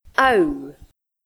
ow